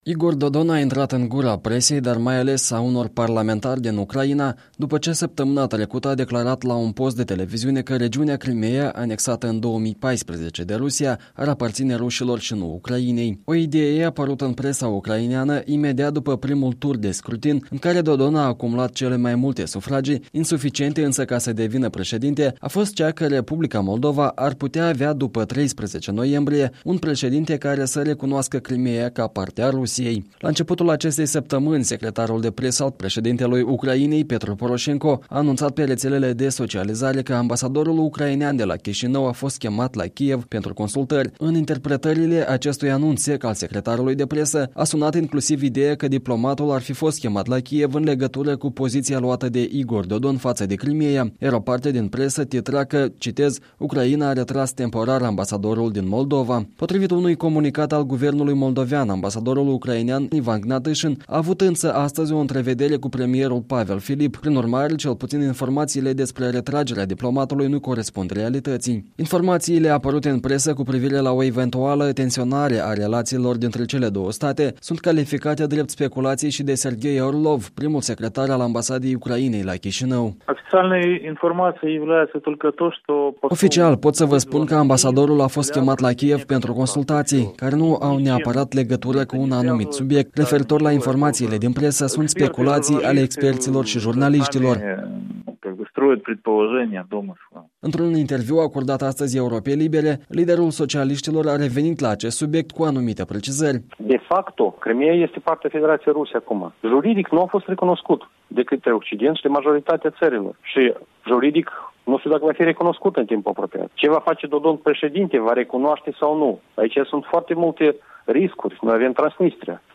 Într-un interviu acordat marți pentru Radio Europa Liberă, candidatul socialist la președinție Igor Dodon a revenit asupra unei declarații legată de statutul Crimeii, pe care unii jurnaliști o interpretaseră ca pe o disponibilitate a liderului socialist să recunoască anexarea peninsulei...